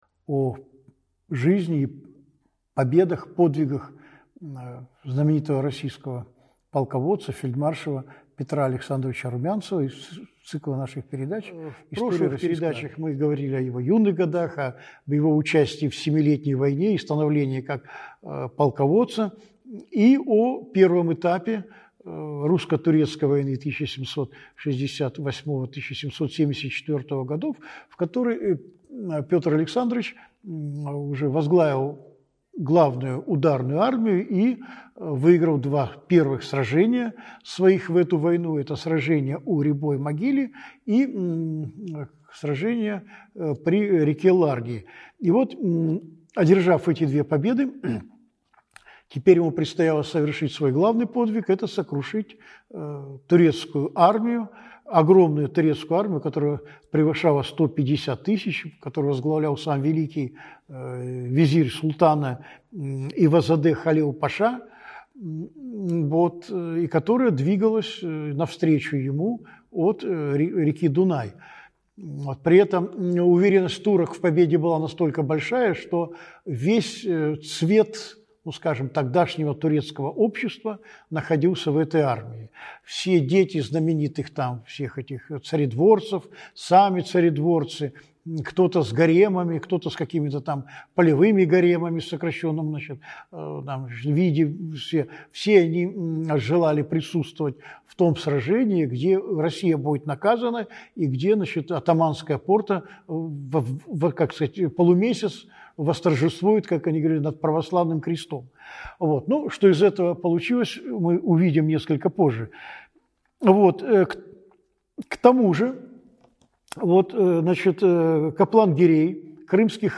Аудиокнига Жизнь и подвиги фельдмаршала Румянцева. Триумф и опала. Часть 2 | Библиотека аудиокниг